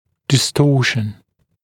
[dɪ’stɔːʃn][ди’сто:шн]искажение, деформация